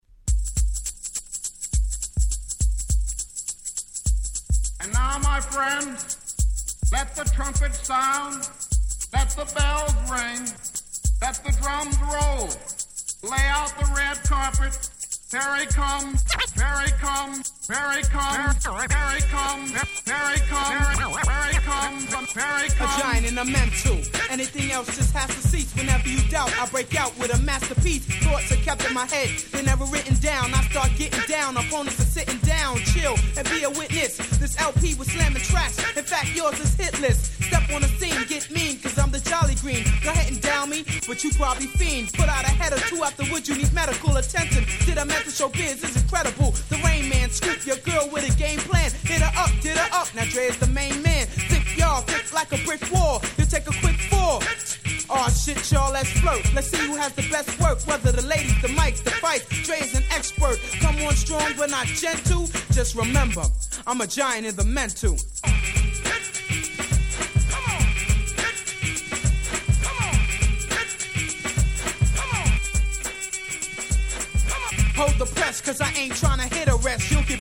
Boom Bap ブーンバップ